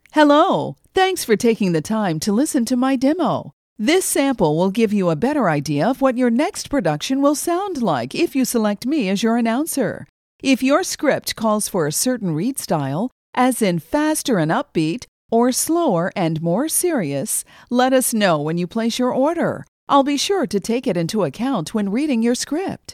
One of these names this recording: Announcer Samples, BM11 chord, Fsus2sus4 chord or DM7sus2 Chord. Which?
Announcer Samples